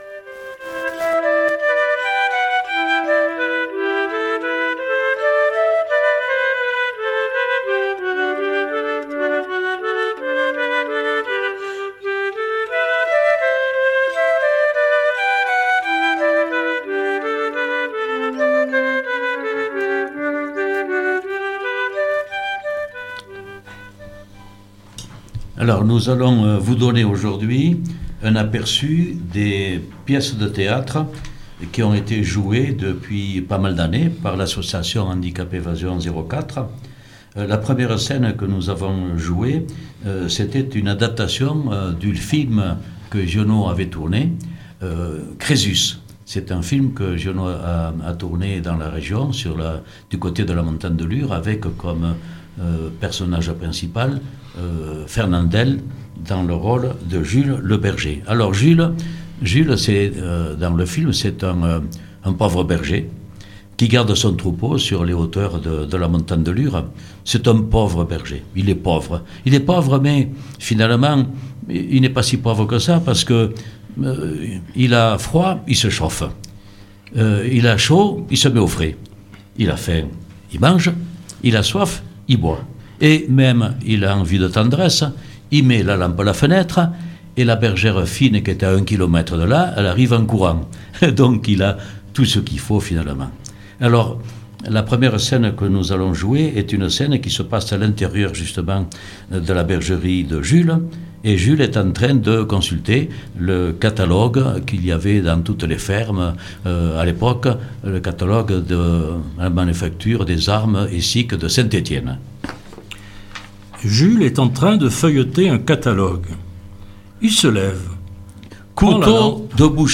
La troupe les "Baladins de la Joëlette" joue Giono !